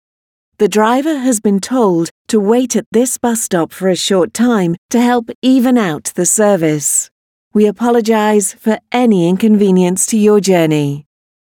Bus-Infotainment--IBus-/assets/audio/manual_announcements/serviceregulation.mp3 at e5a8d78bf128a8edcf4c44b64cb6b2afcdac202e